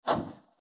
دانلود آهنگ دعوا 8 از افکت صوتی انسان و موجودات زنده
دانلود صدای دعوای 8 از ساعد نیوز با لینک مستقیم و کیفیت بالا
جلوه های صوتی